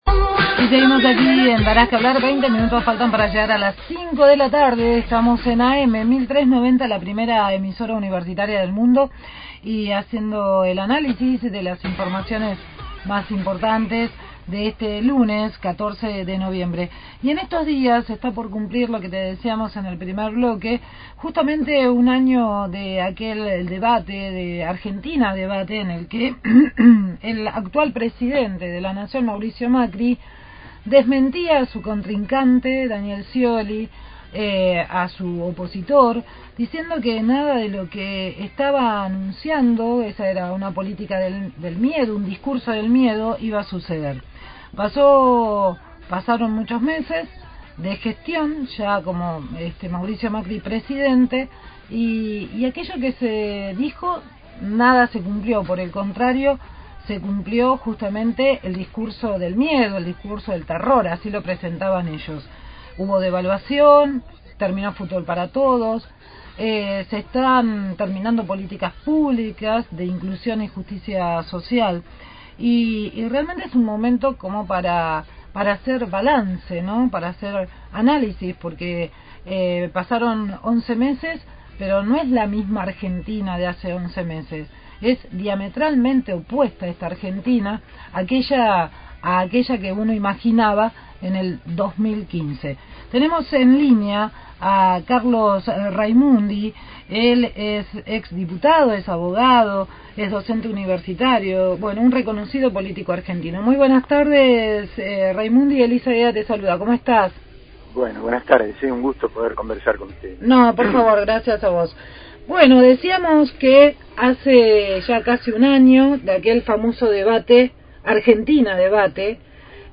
Carlos Raimundi, ex diputado, abogado y docente universitario, dialogó con el equipo de «Darás que hablar» y analizó la gestión del nuevo gobierno a un año del debate presidencial entre Daniel Scioli y Mauricio Macri.